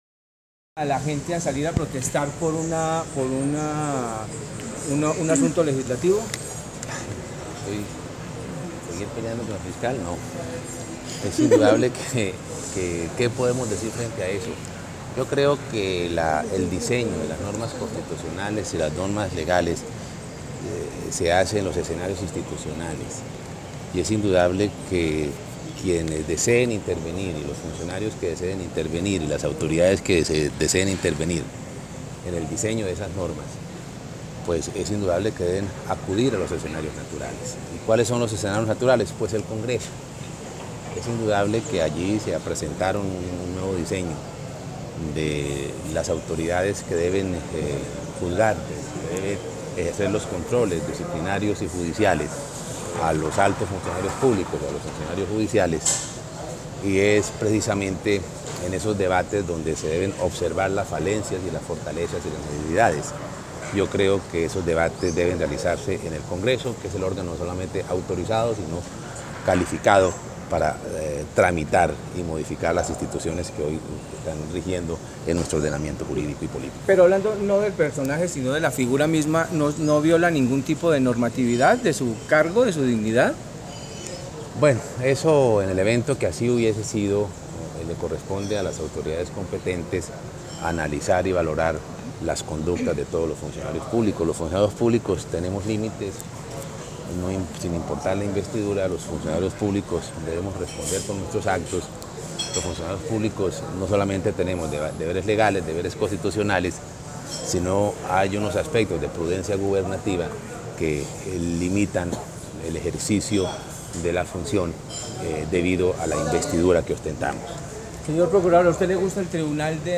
documento-declaraciones(todosestamos)-14.mp3